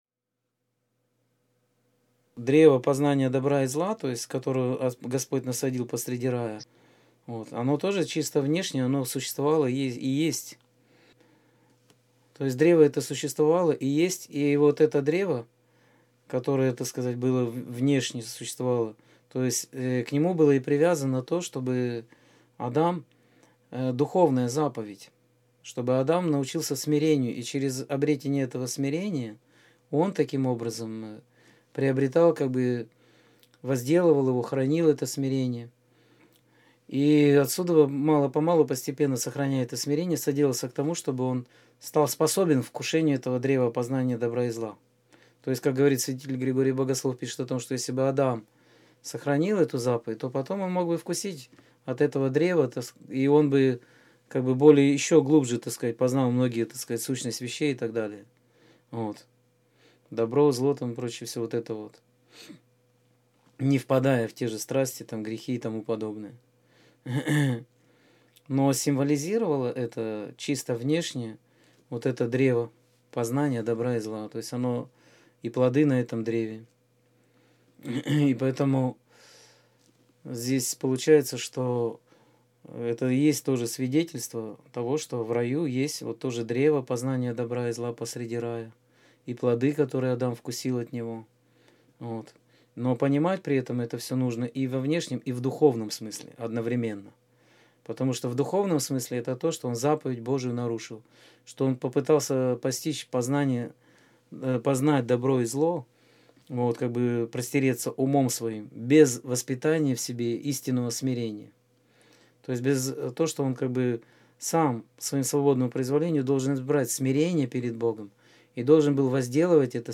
Скайп-беседа 17.05.2014